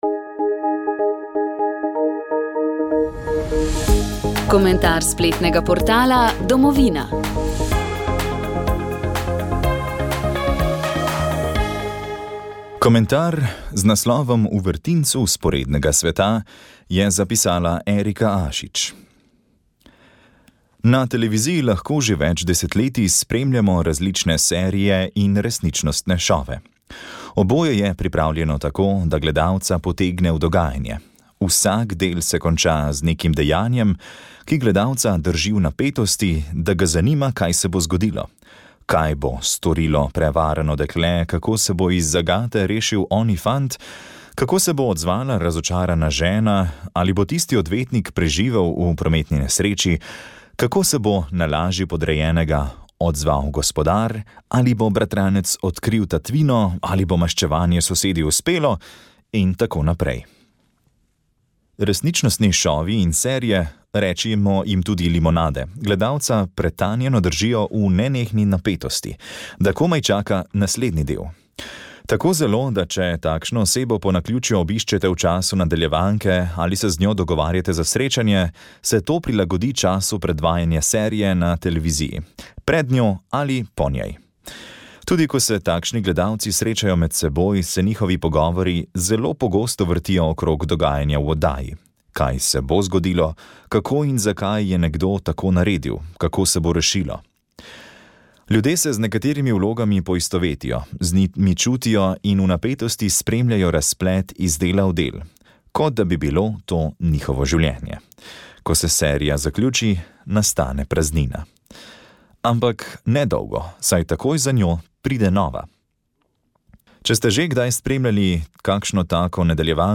Sv. maša iz cerkve sv. Marka na Markovcu v Kopru 19. 8.